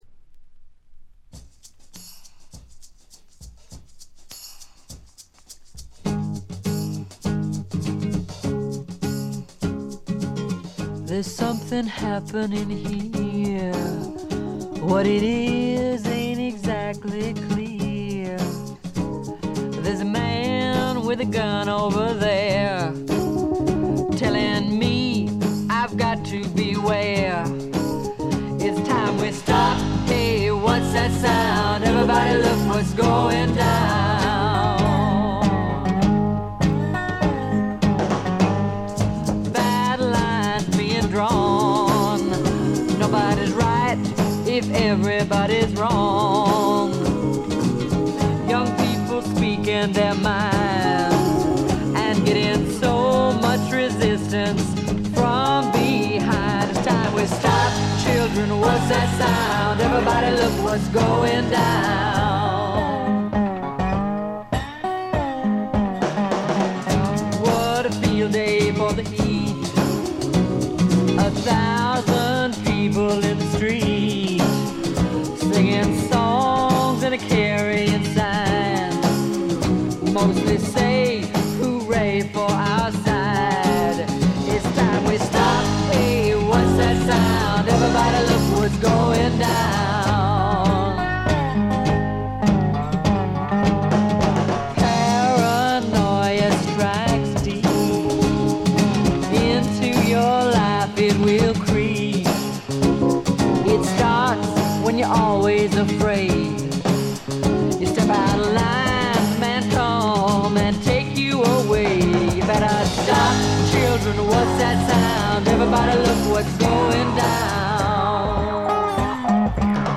ほとんどノイズ感無し。
試聴曲は現品からの取り込み音源です。
Recorded At - Muscle Shoals Sound Studios